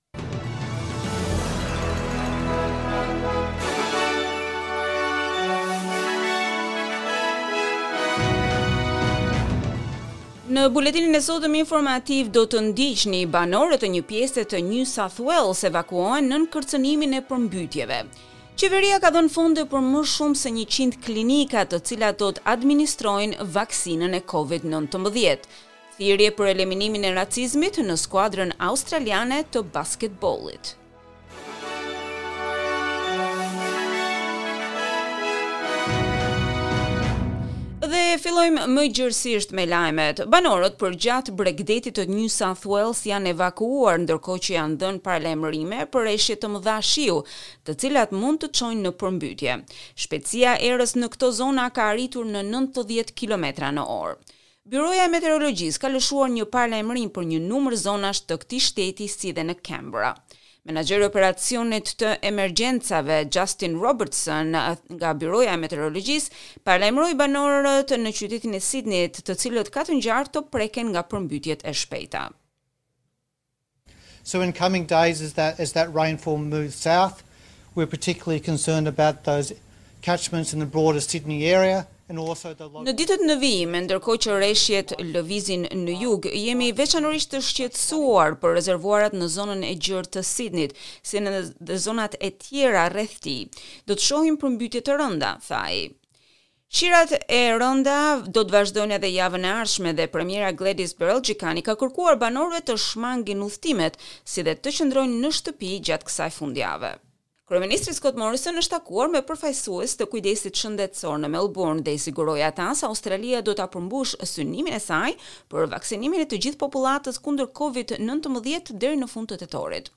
SBS News Bulletin in Albanian - 20 March 2021